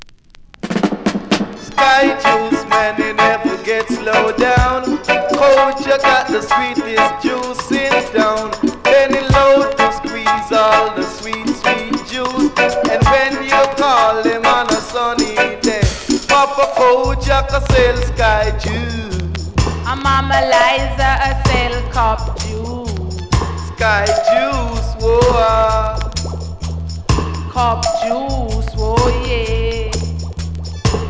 7"/Vintage-Dancehall